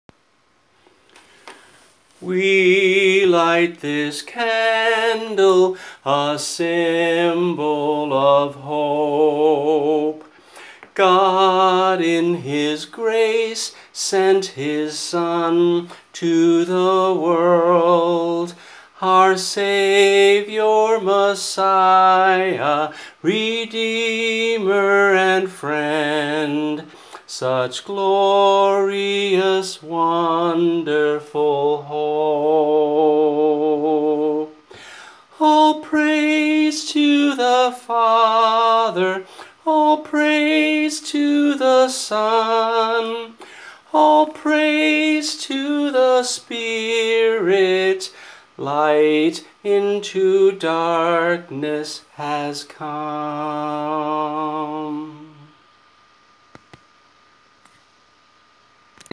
(a song for Advent)